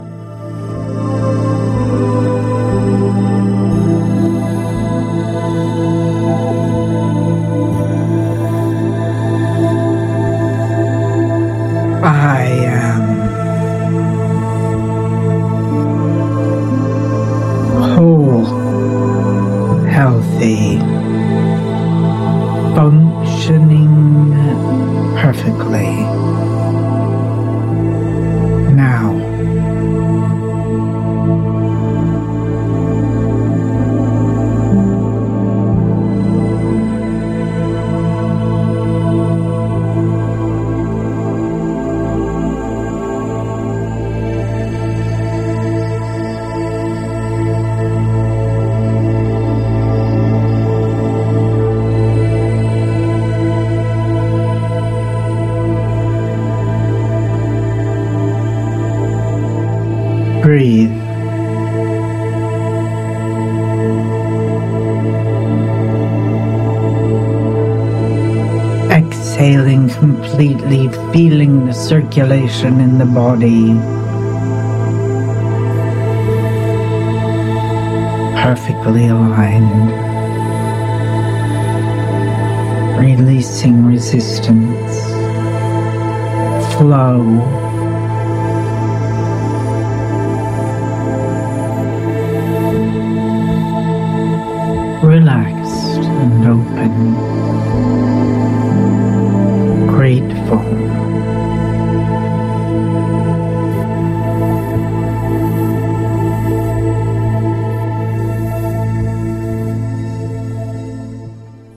Guided Meditation 2